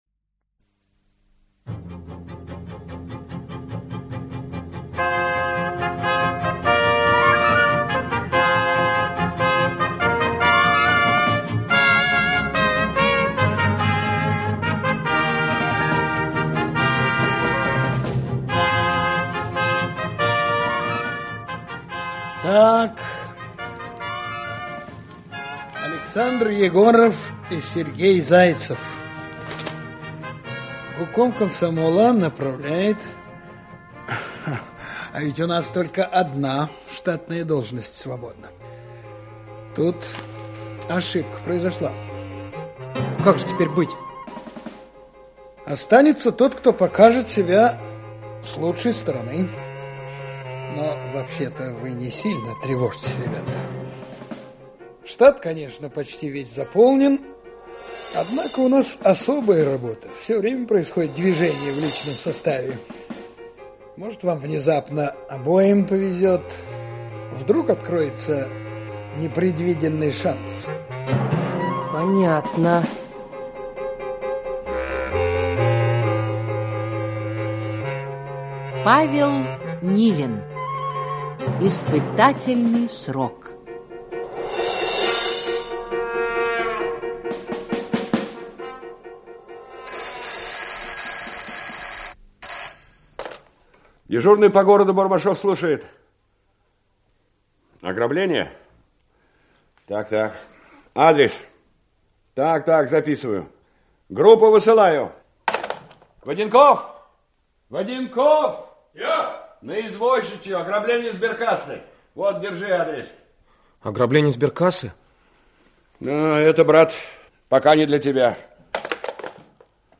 Аудиокнига Испытательный срок. Часть 1 | Библиотека аудиокниг
Часть 1 Автор Павел Филиппович Нилин Читает аудиокнигу Лев Дуров.